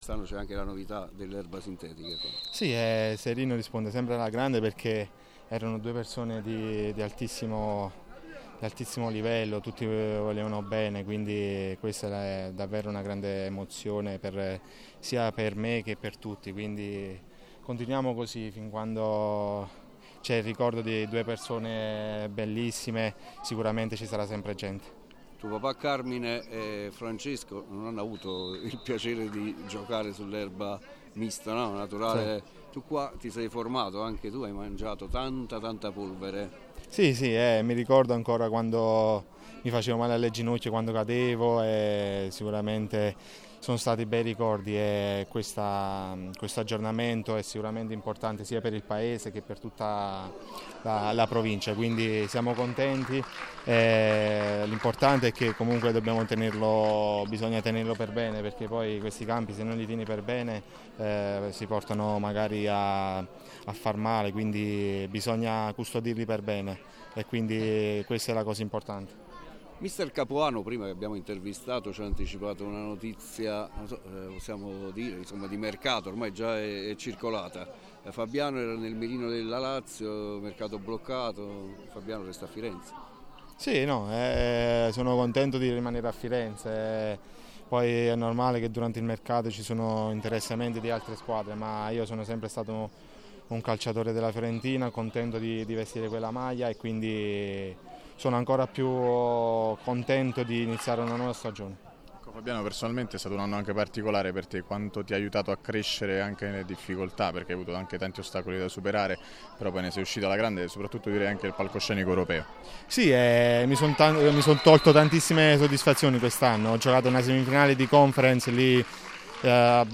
PODCAST | RIASCOLTA LE DICHIARAZIONI RILASCIATE DA FABIANO PARISI